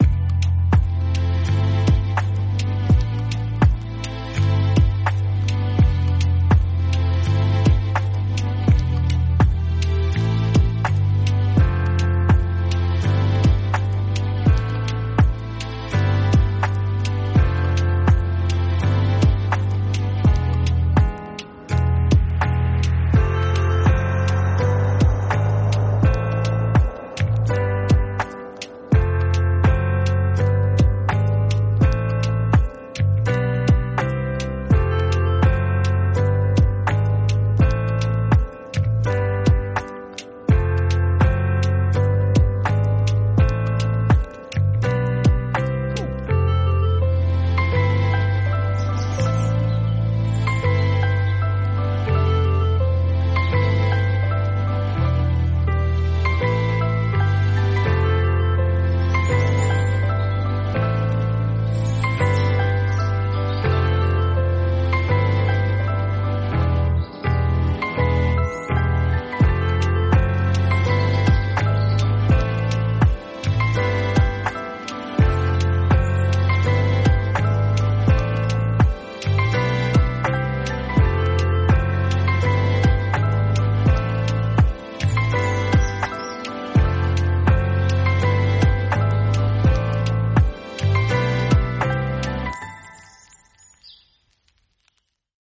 calming track